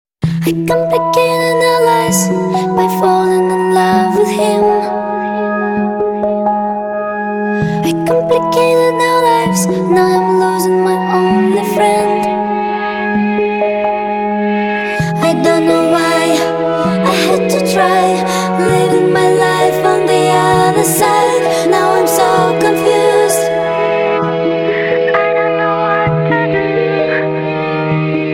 这里是这首歌的铃声，只是其中一段。